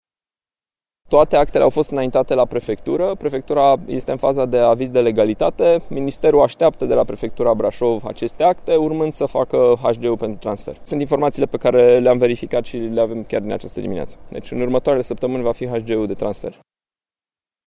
Primarul municipiului Brașov, Allen Coliban: